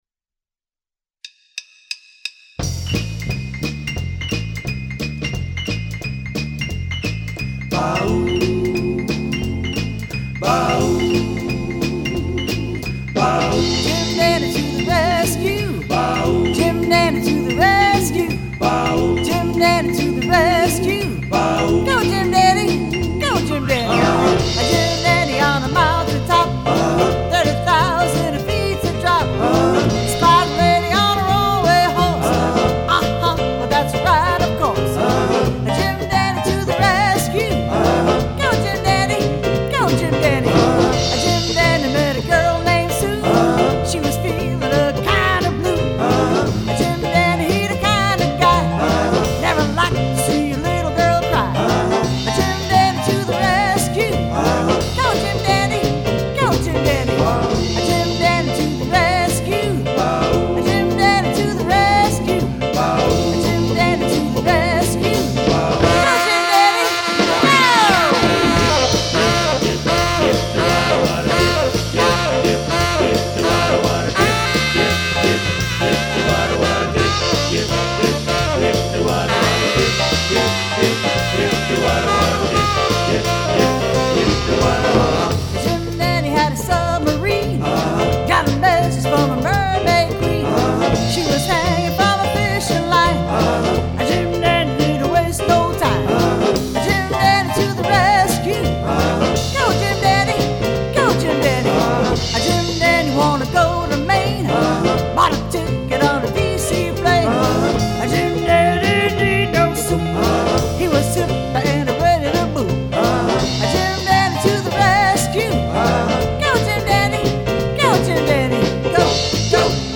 Need Critique on my amatuer recording & mix